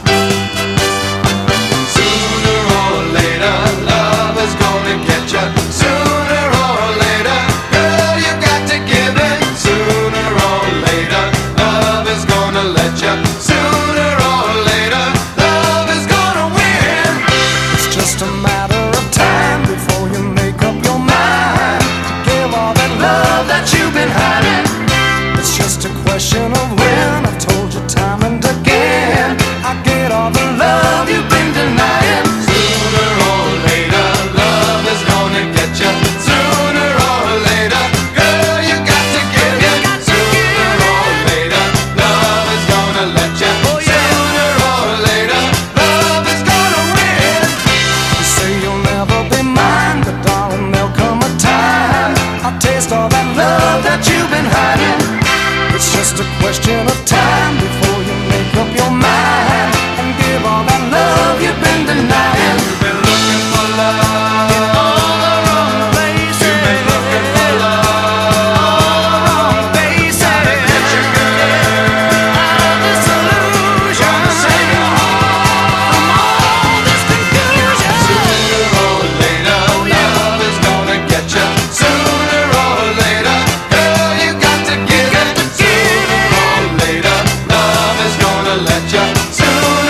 ROCK / S.S.W./A.O.R. / BLUE EYED SOUL
プリA.O.R.～ブルーアイド・ソウルな2NDソロ！